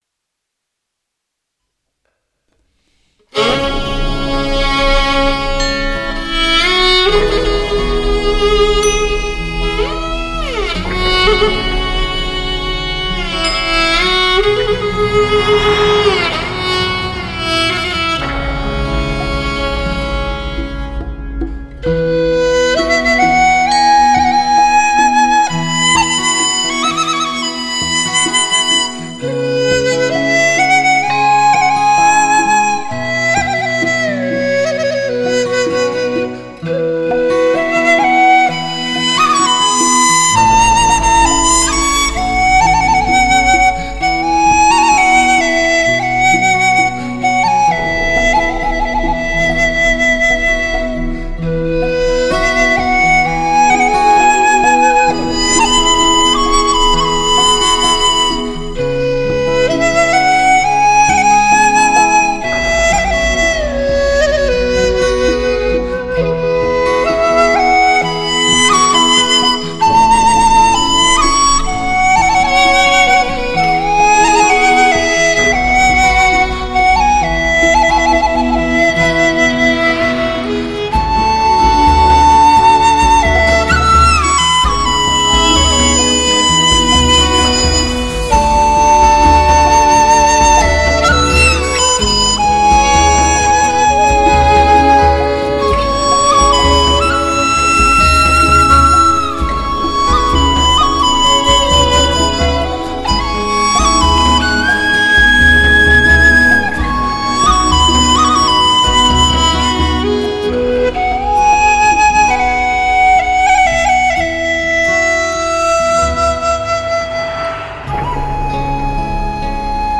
全球首张纯民乐环绕声DTS6.1CD，经典之作，完美音质，非一般的感受。
笛子